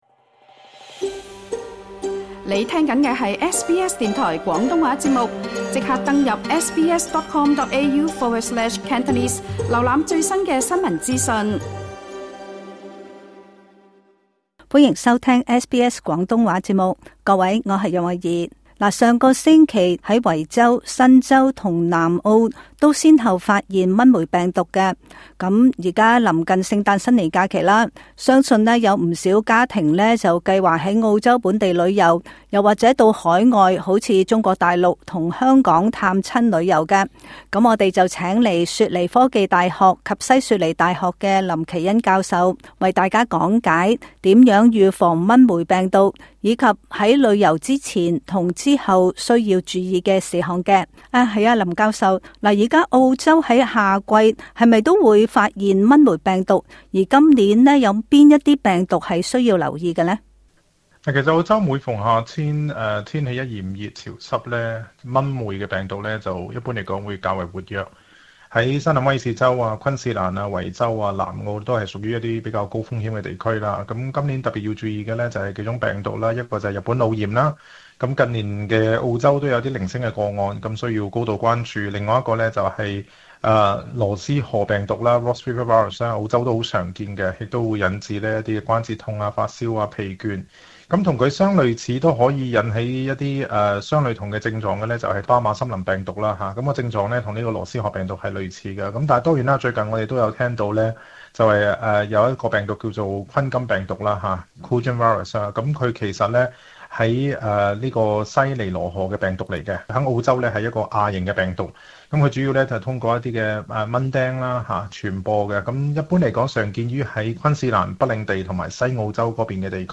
詳情請聽今集訪問。